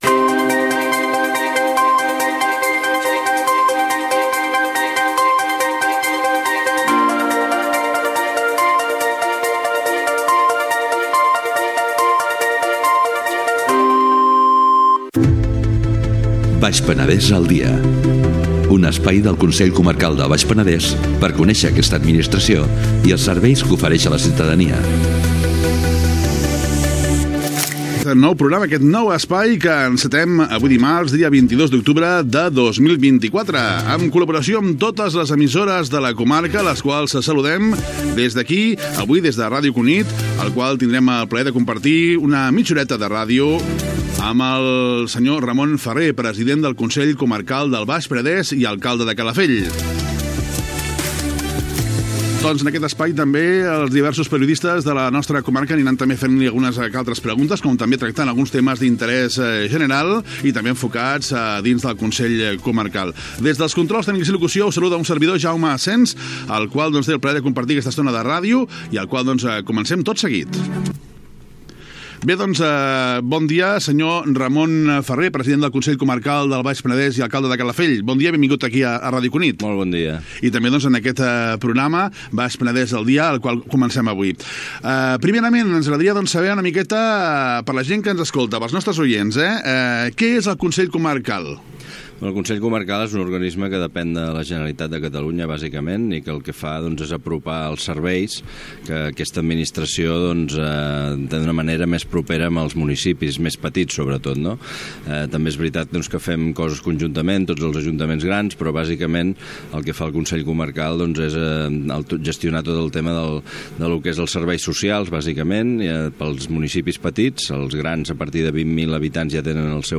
Careta del programa, data, presentació i fragment d'una entrevista a Ramon Ferré, president del Consell Comarcal del Baix Penedès i alcalde de Calafell
Informatiu